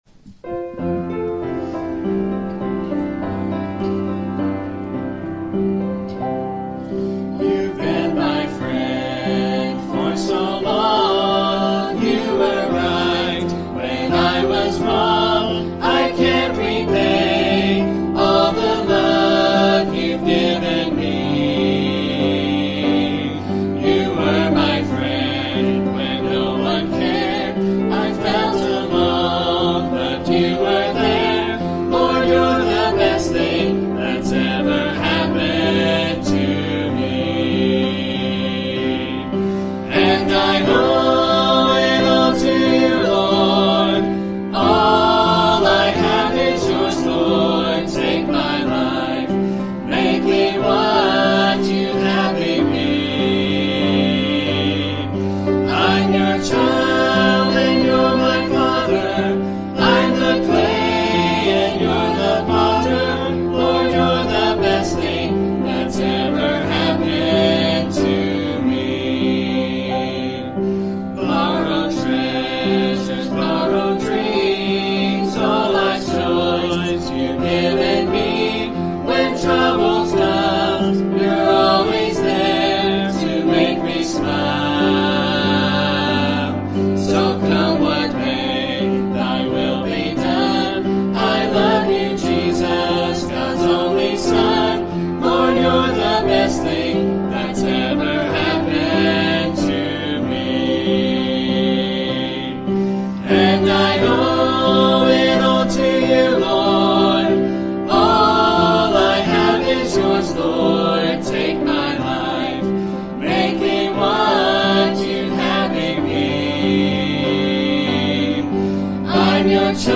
Mixed Group